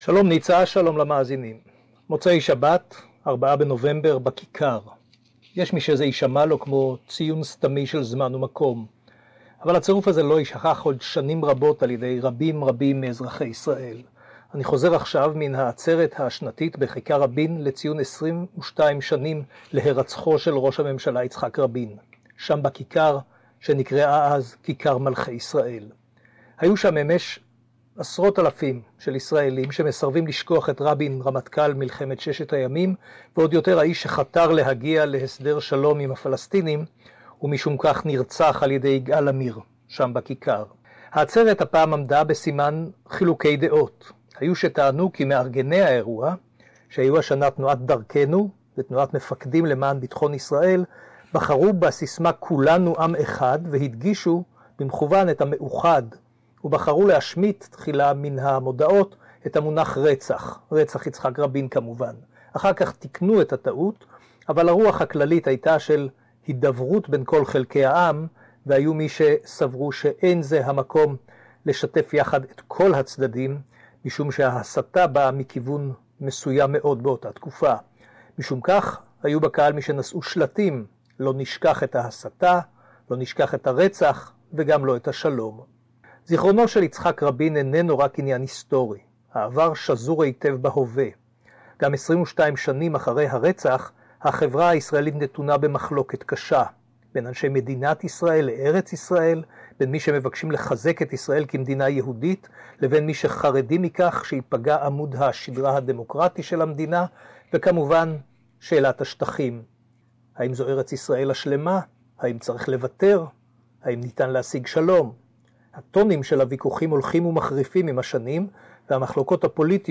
reports from us from Kikar Rabin in Tel Aviv with the latest news from Isael...